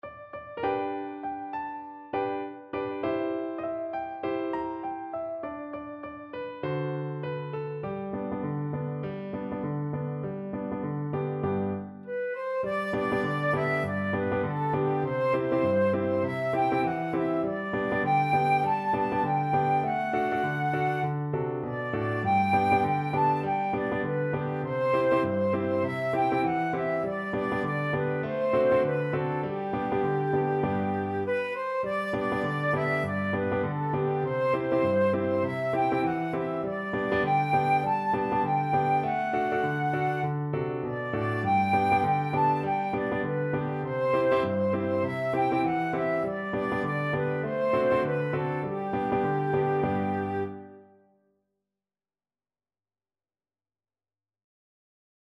Flute
G major (Sounding Pitch) (View more G major Music for Flute )
4/4 (View more 4/4 Music)
Swing 16, =100